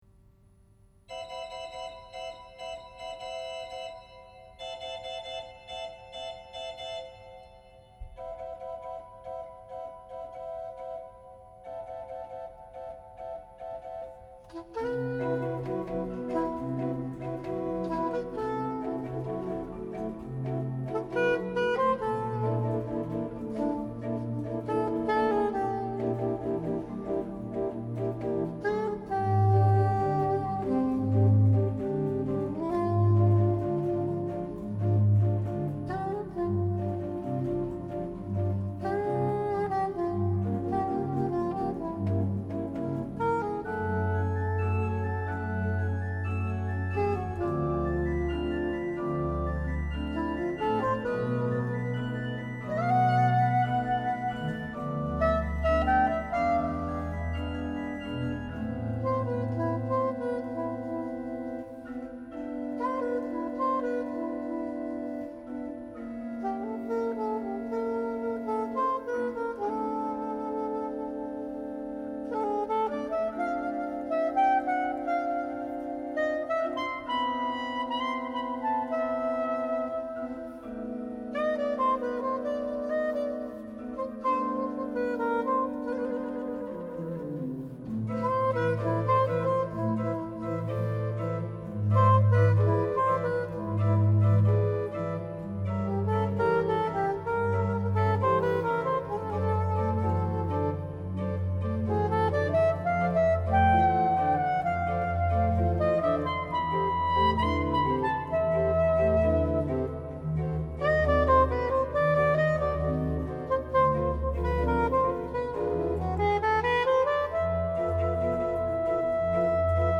en la cathédrale de Saint-Bertrand de Comminges
le tout donnant la part belle à l'improvisation et au jazz.